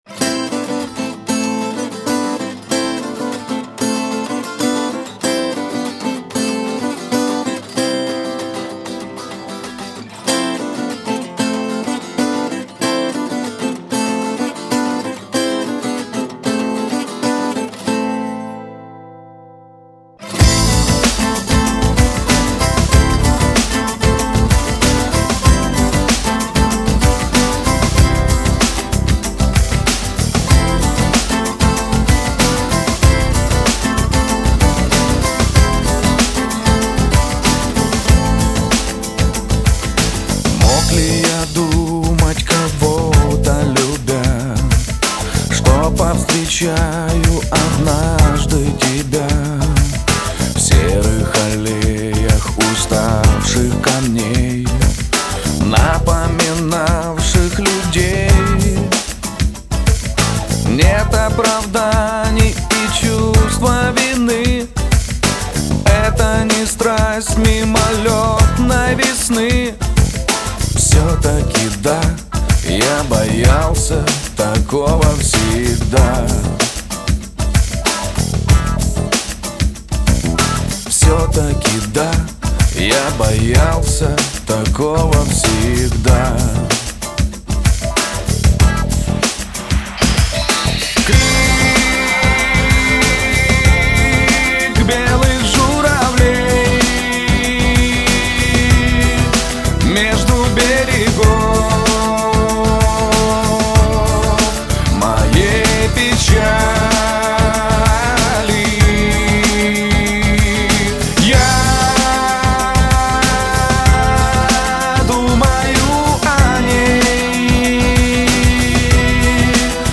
Головна » Файли » Музика » Спокійні